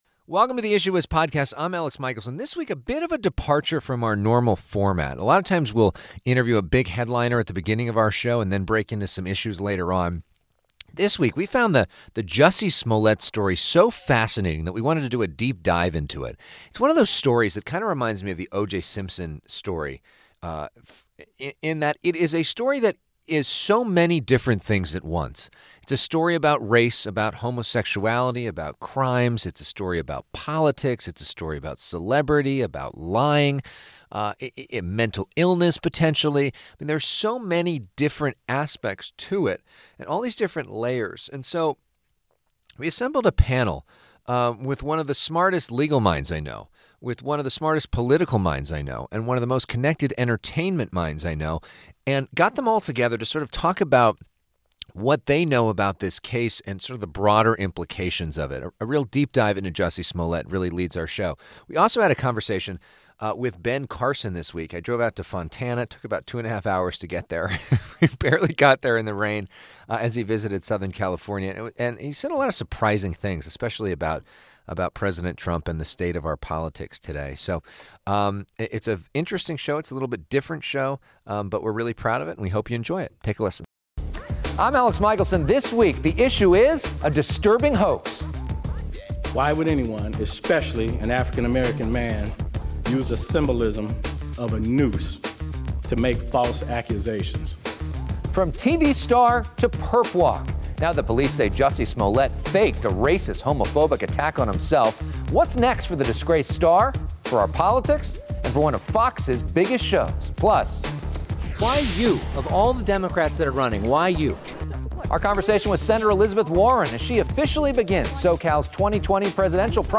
Jussie Smollett Roundtable Discussion
Now the police say Jussie Smollett faked a racist, homophobic attack on himself. What's next for the disgraced star? Plus, an interview with presidential candidate Sen. Elizabeth Warren.